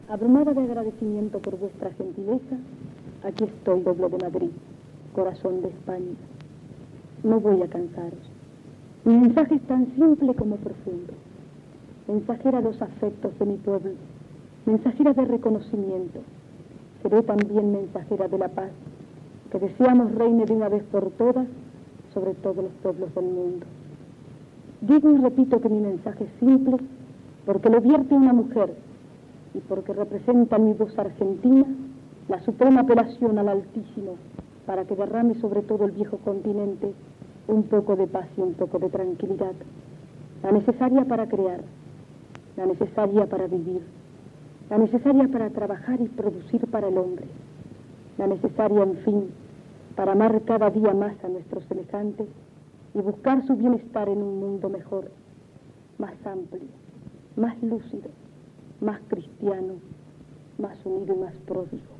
Visita de la primera dama argentina Eva Duarte de Perón a Espanya, paraules des del Palacio del Pardo.
Informatiu